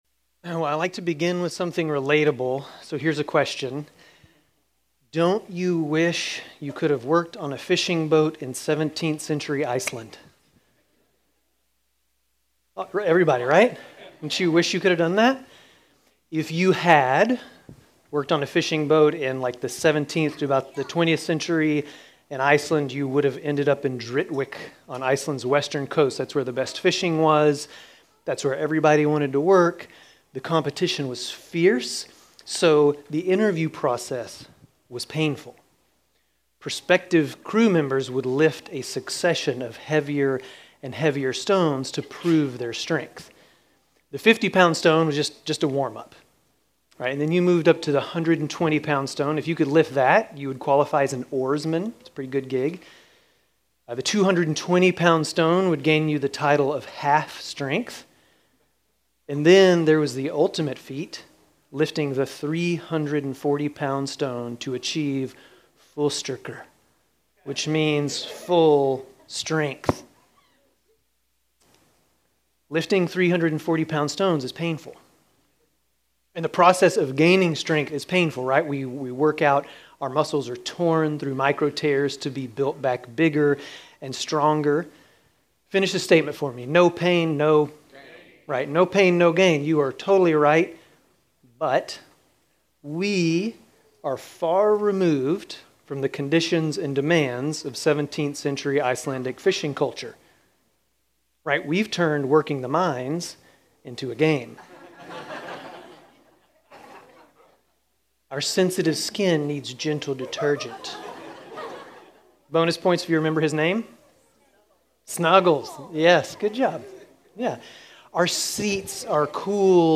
Grace Community Church Dover Campus Sermons 6_15 Dover Campus Jun 16 2025 | 00:28:13 1x 00:00 / 00:28:13 Subscribe Share RSS Feed Share Link Embed